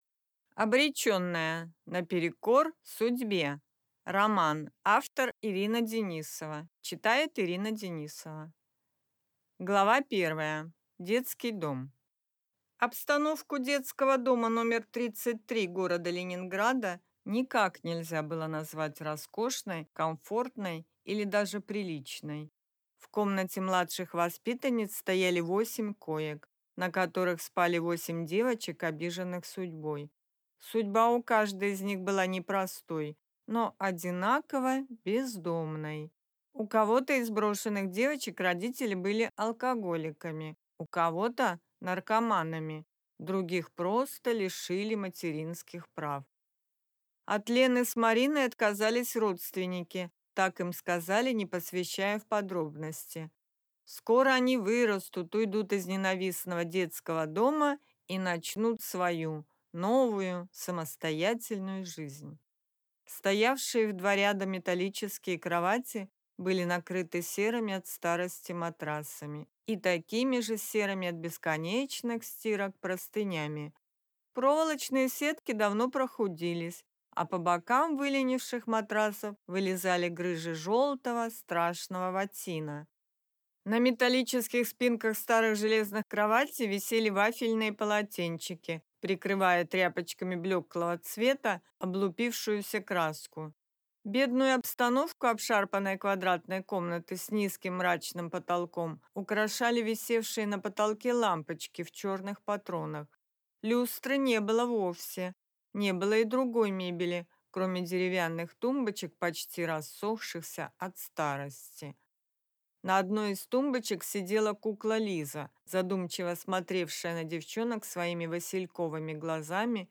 Аудиокнига Обреченная. Наперекор судьбе | Библиотека аудиокниг